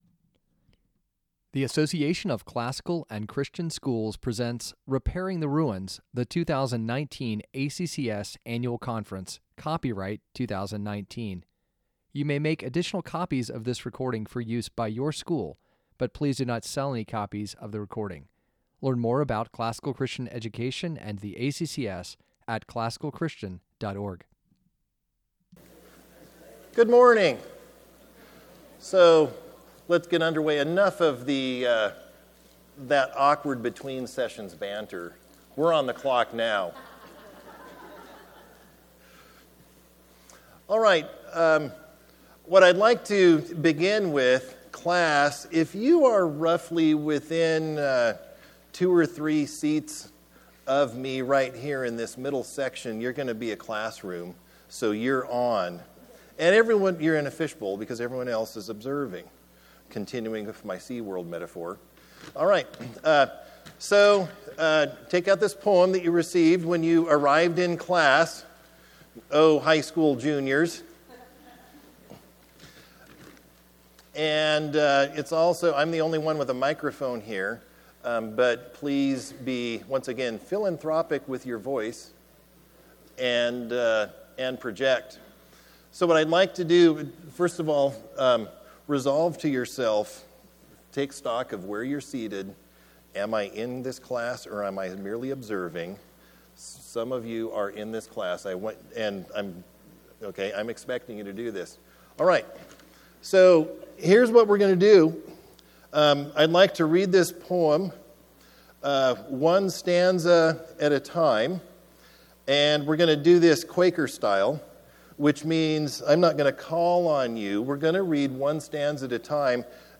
2019 Foundations Talk | 01:00:20 | 7-12, Teacher & Classroom, General Classroom